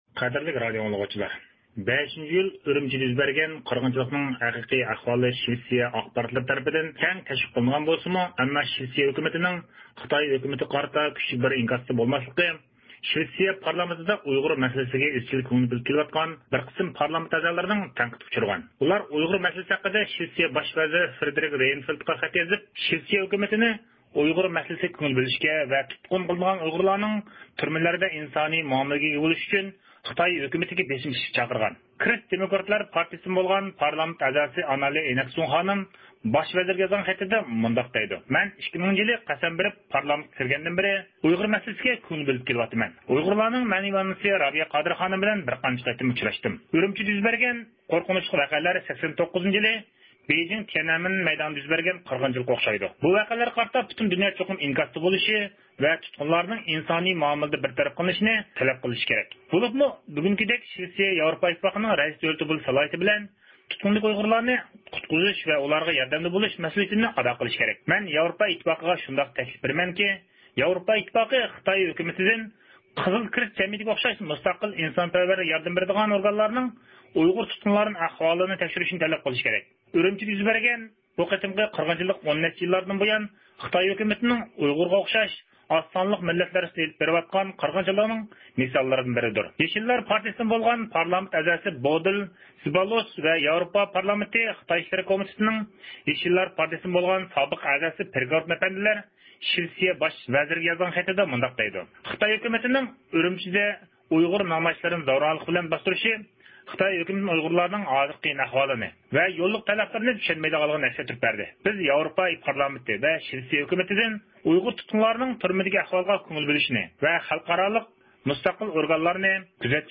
كوچىدا زىيارىتىمىزنى قوبۇل قىلغان بىر شىۋىت بۇ مەسىلە ھەققىدە كۆز قارىشىنى بايان قىلىپ مۇنداق دەيدۇ: «ئۇيغۇرلار ھەققىدە سىياسەتچىلەرنىڭ بىلىمىنىڭ كامچىل بولۇشى ۋە ھازىرقى ئىقتىسادىي كرىزىسنى چۆرىدىگەن بىر قاتار مەسىلىلەر شىۋېتسىيە ھۆكۈمىتىنىڭ ئۈرۈمچى ۋەقەسىگە قارىتا ئاكتىپ بىر ئىنكاستا بولماسلىقىنىڭ ئاساسى سەۋەبى بولۇشى مۇمكىن.